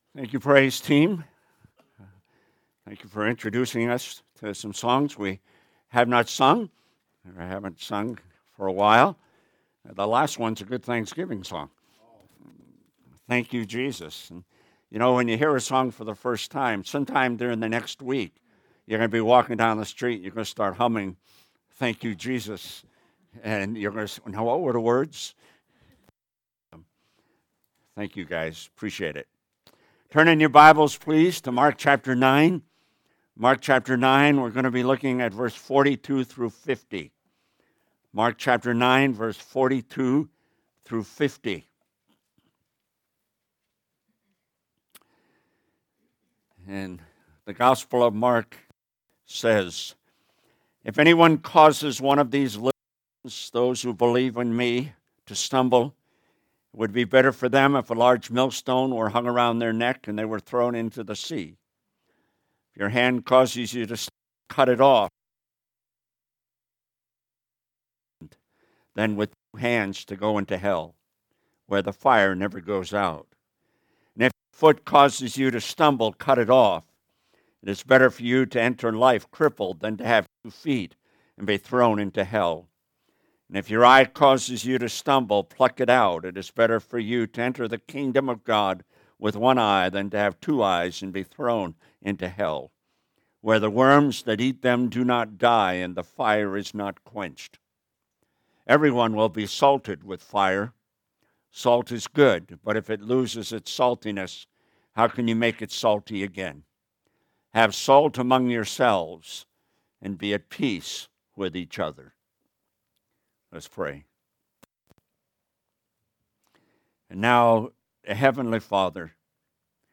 Sermons - Pleasant Prairie Baptist Church